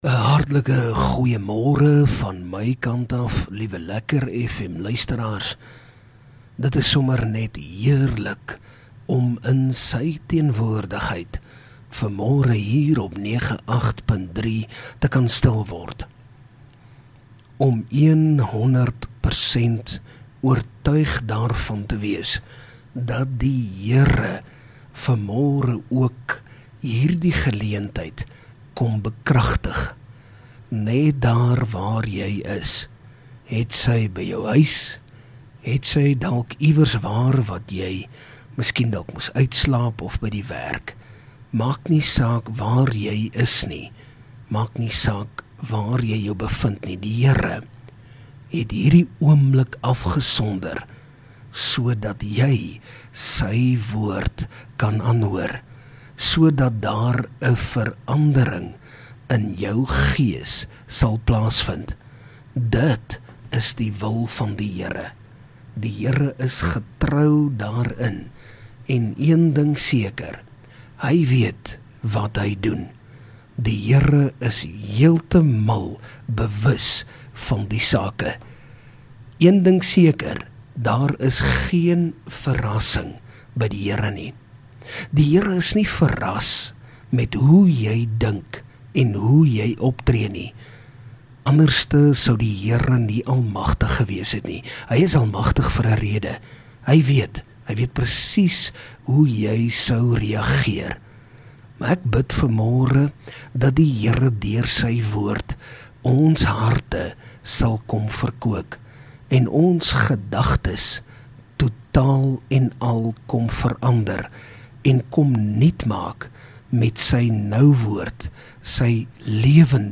LEKKER FM | Oggendoordenkings 13 Feb Past.